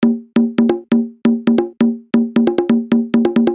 Lite Conga Loop 2 135bpm.wav